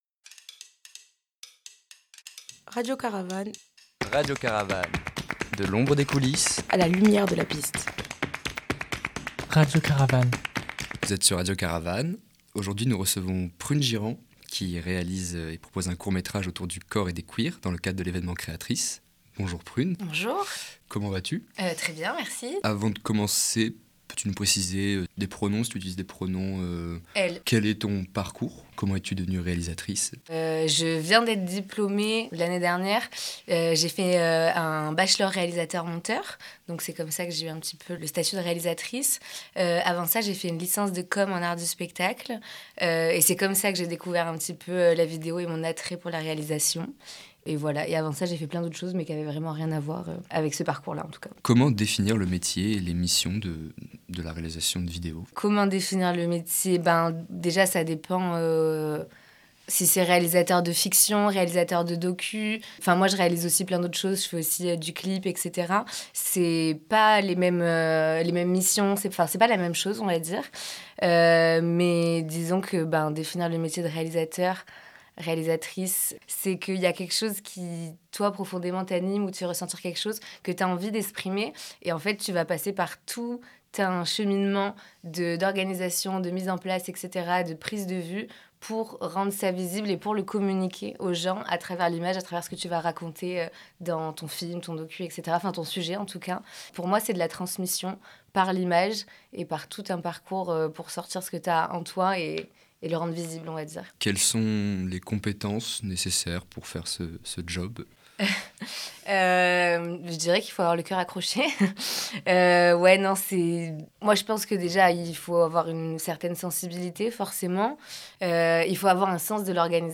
Pour cette occasion, nous lui avons proposé de venir en discuter au studio en interview. Nous avons découvert sa passion pour les mouvements des corps, que ce soit en cirque, en danse, etc, mais également sa volonté de visibiliser les minorités de genres.